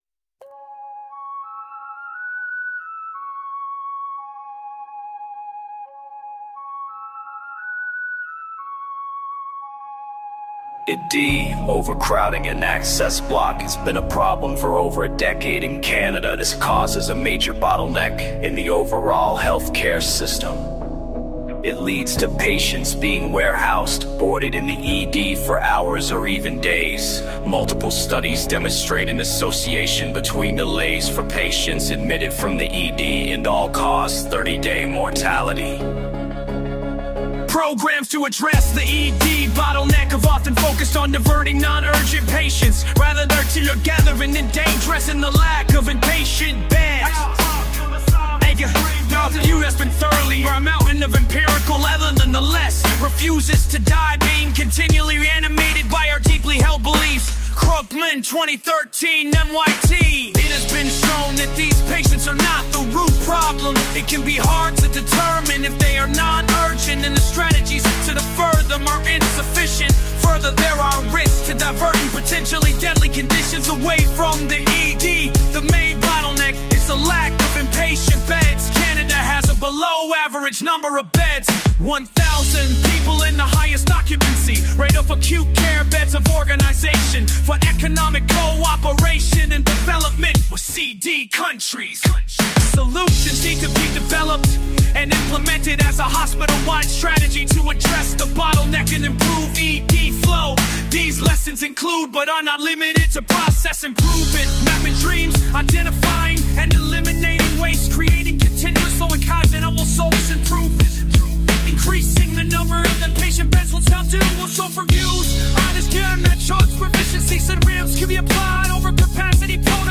I also created a song to summarize some of the important parts of this issue (
She showed me how to make a song using artificial intelligence.